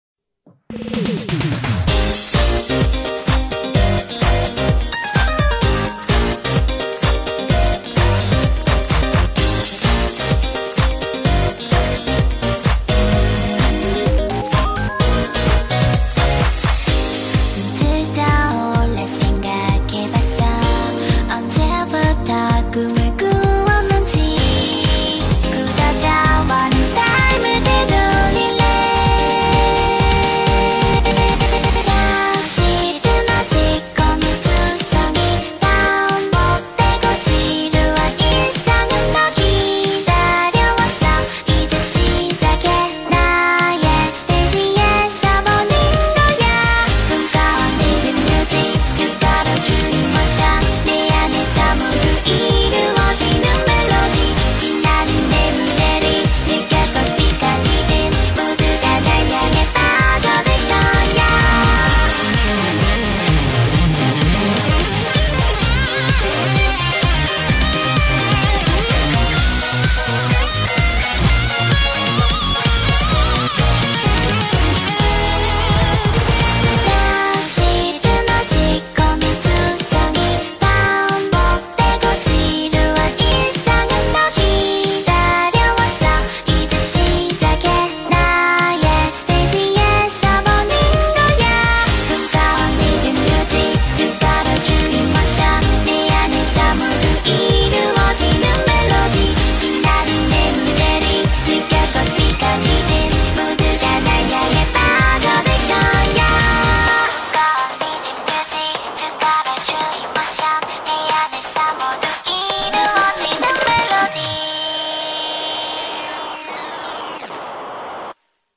BPM128
MP3 QualityLine Out